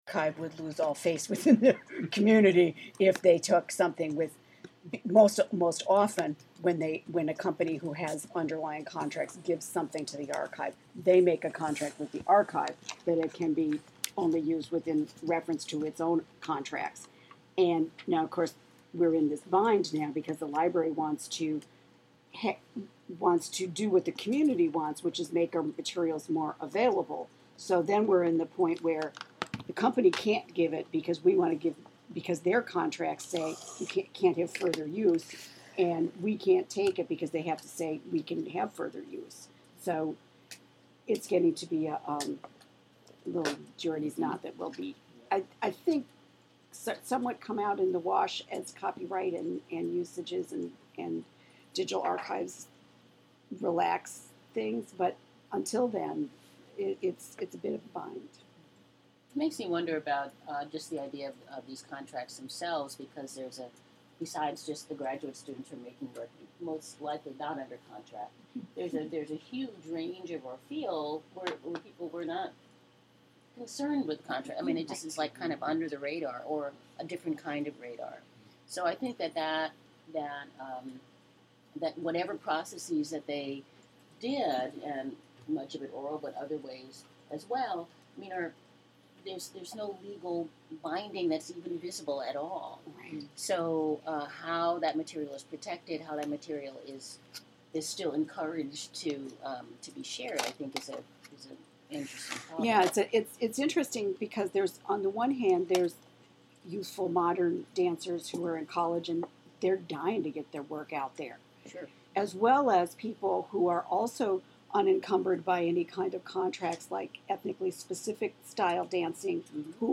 01_Discussion of_Dance_Contracts.mp3